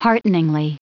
Prononciation du mot hearteningly en anglais (fichier audio)
Prononciation du mot : hearteningly